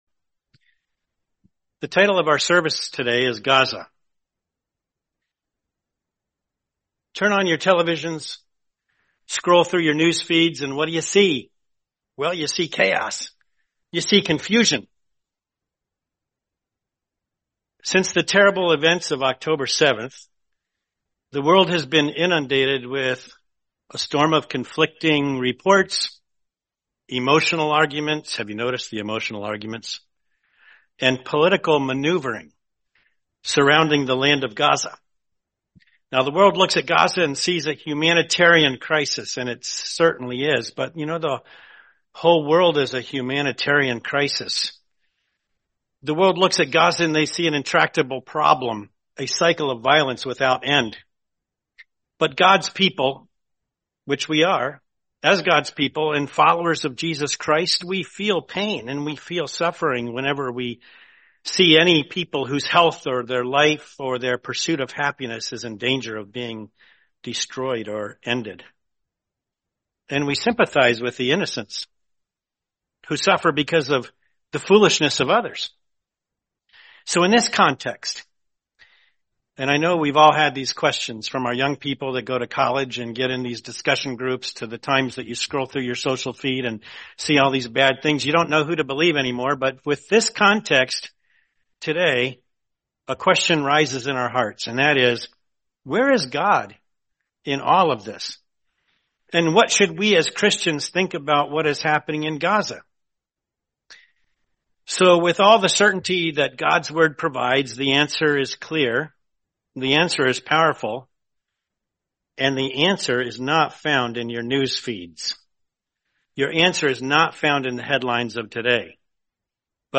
Series: 2025 Milwaukee Convention